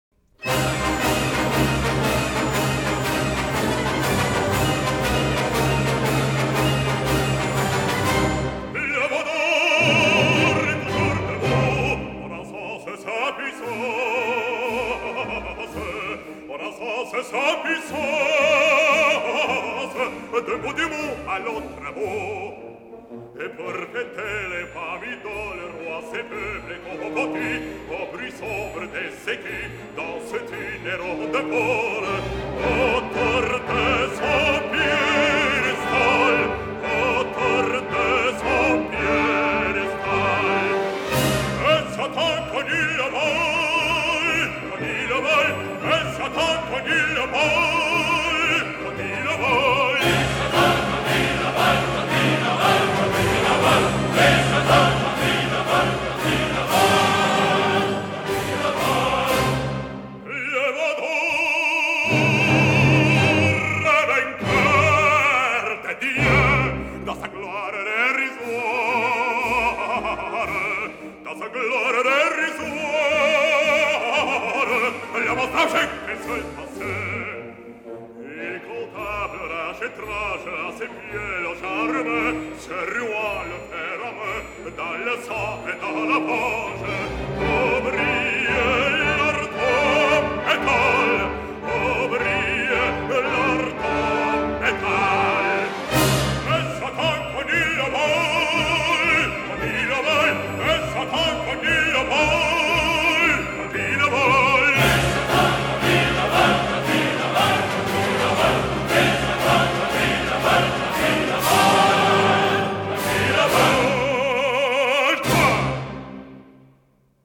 试听为歌剧中芭蕾音乐片断和多个唱段链接：